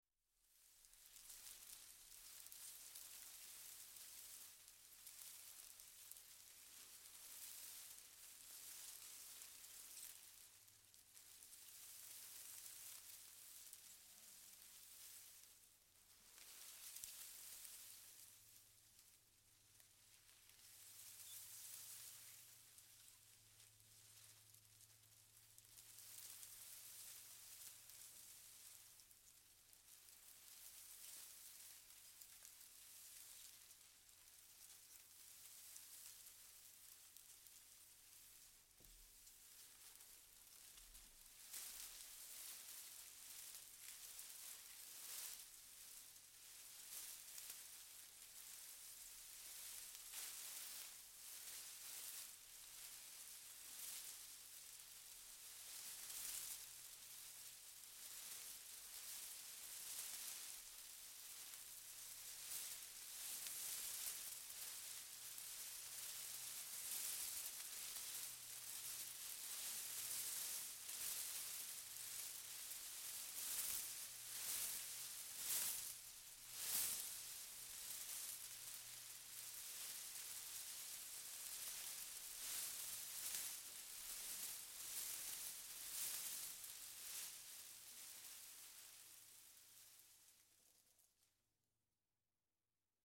Здесь вы можете скачать или послушать онлайн странные шумы, шаги и другие аудиофрагменты.
Звук, будто в углу комнаты домовой шелестит чем-то